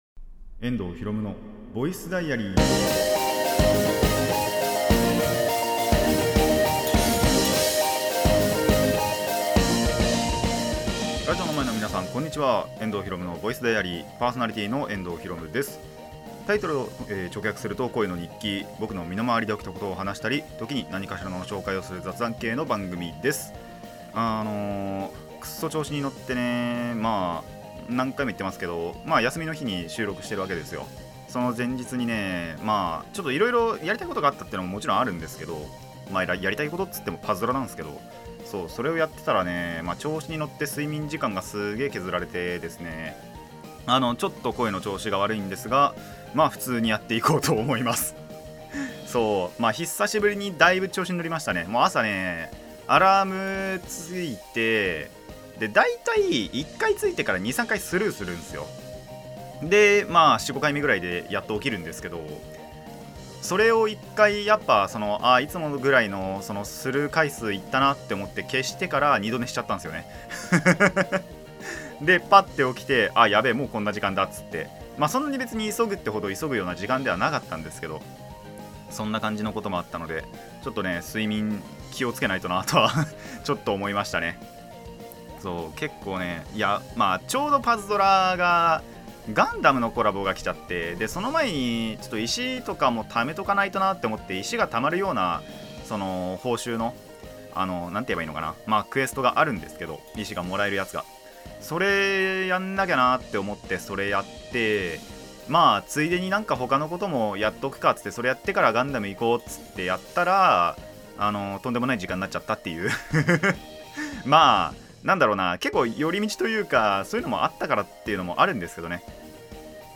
(Bilingual)